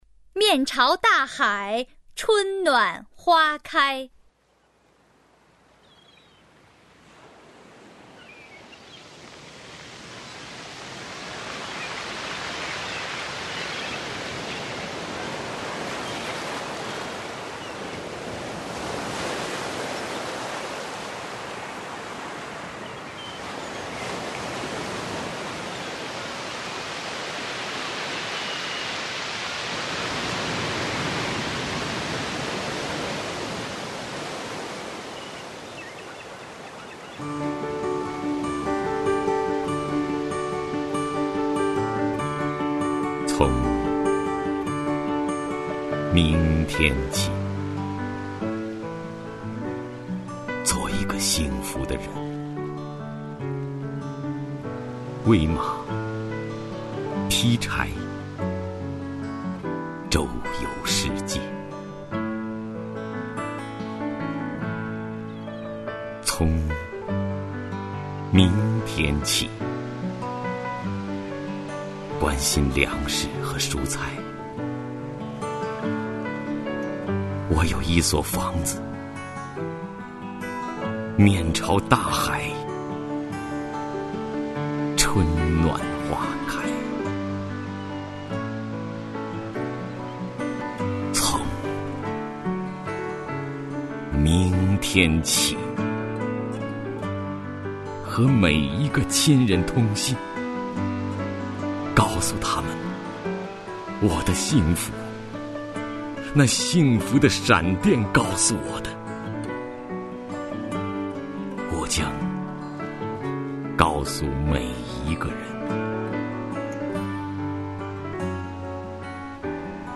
首页 视听 名家朗诵欣赏 徐涛
徐涛朗诵：《面朝大海，春暖花开》(海子)